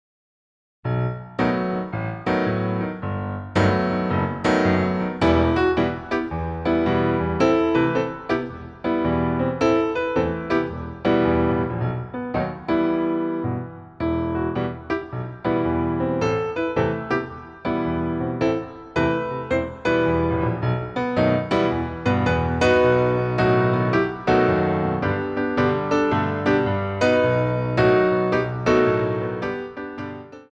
BATTEMENT TENDU I